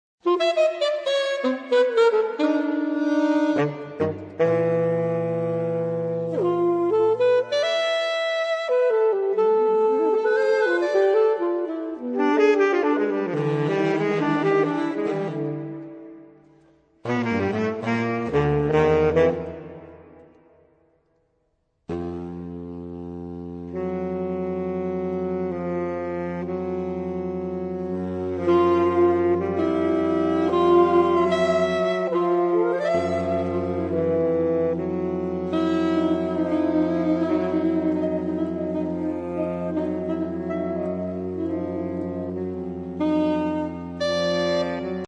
Obsazení: 4 Saxophone (AATBar)